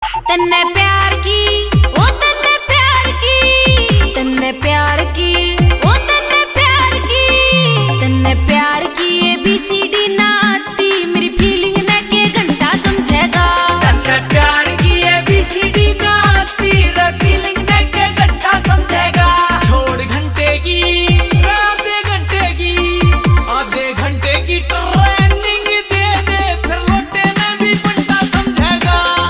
Haryanvi Songs
• Simple and Lofi sound
• Crisp and clear sound